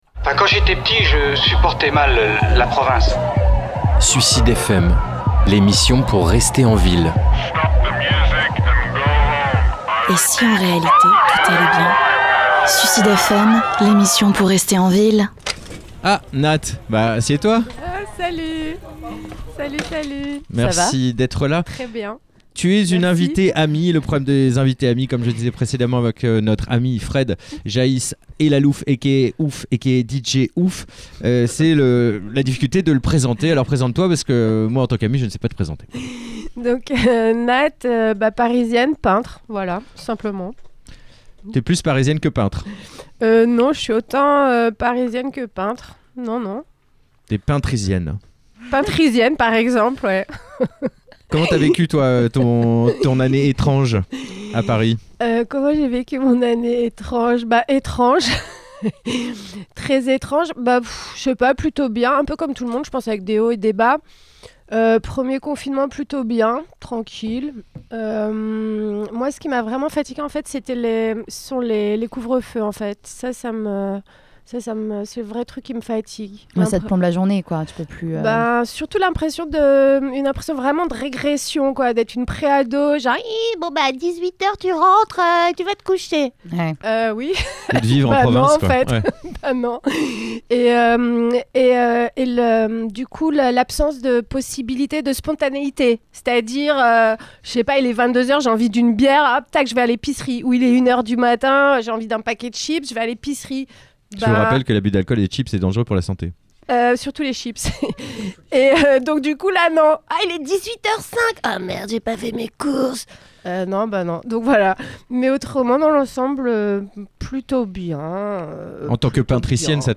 Interview part 1